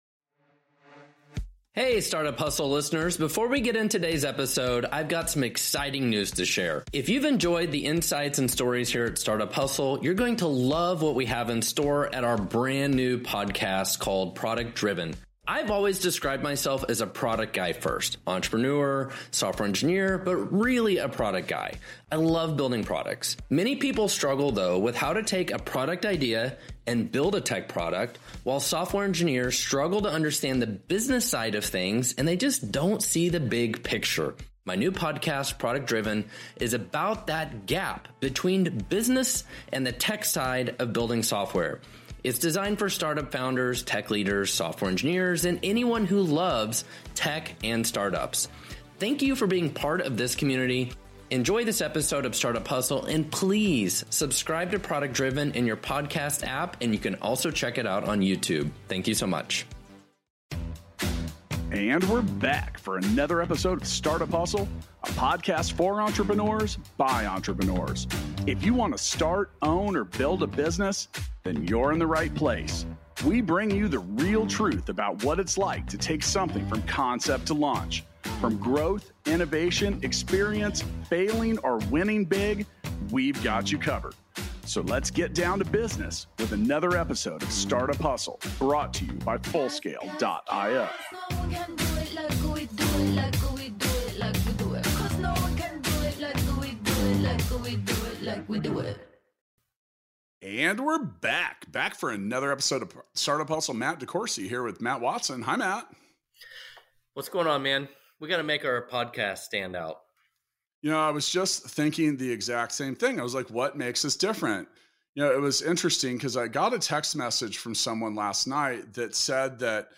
The dynamic duo is back behind the mic together! Discover the elements that will make your company stand out and, in turn, help you better serve your clients.